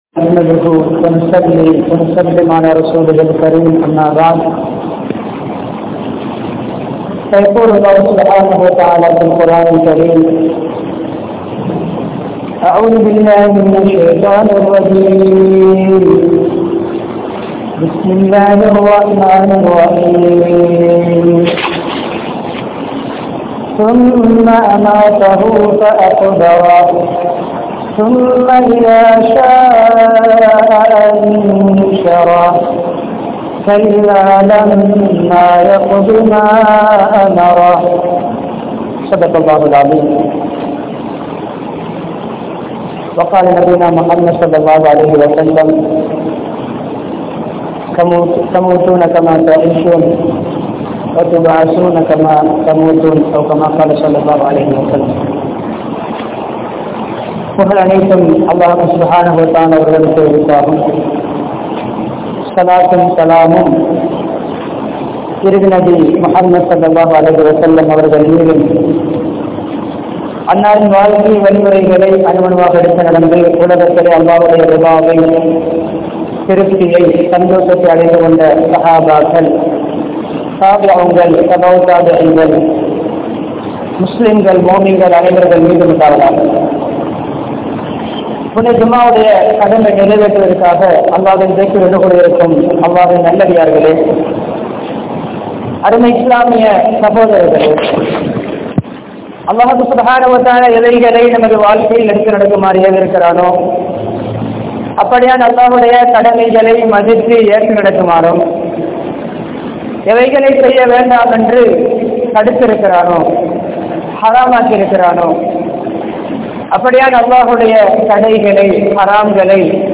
Kaburudaiya Vaalkai (கப்ருடைய வாழ்க்கை) | Audio Bayans | All Ceylon Muslim Youth Community | Addalaichenai
Peliyagoda Jumua Masjidh